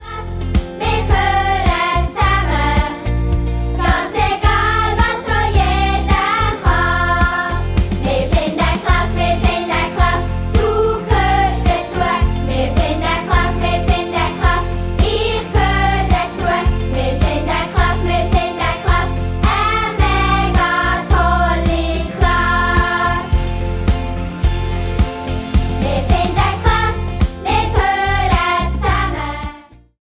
Musikalisches Hörspiel zum Schulstart